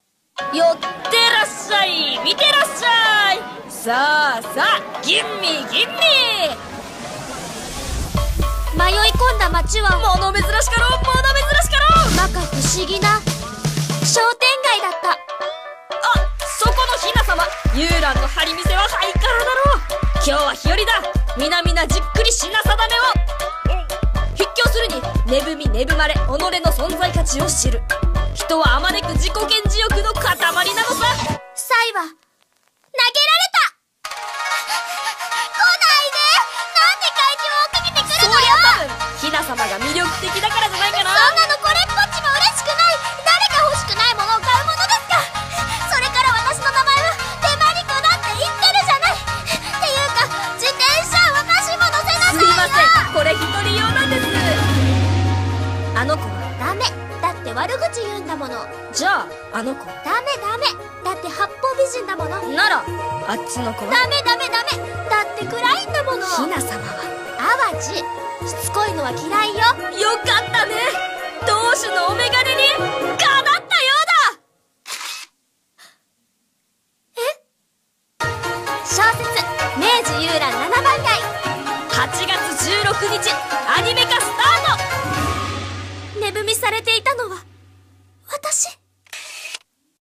CM風声劇「明治ゆうらん七番街」